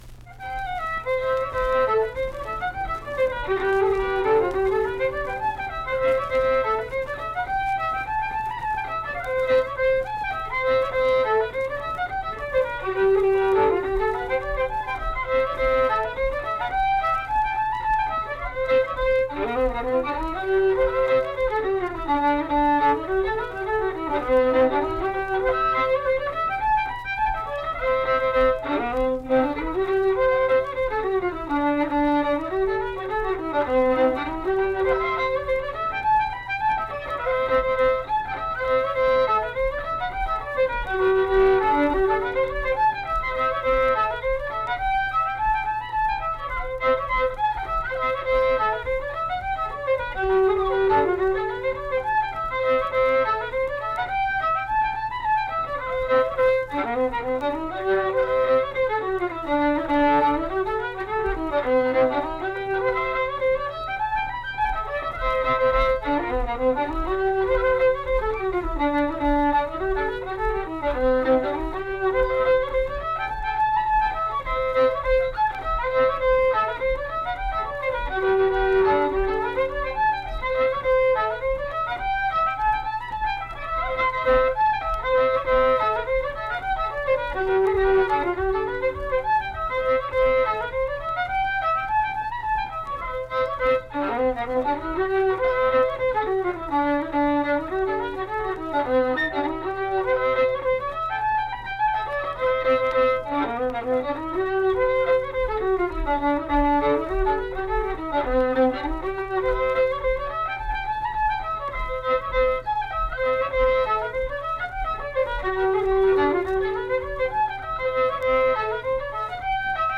Unaccompanied fiddle music
Instrumental Music
Fiddle
Mill Point (W. Va.), Pocahontas County (W. Va.)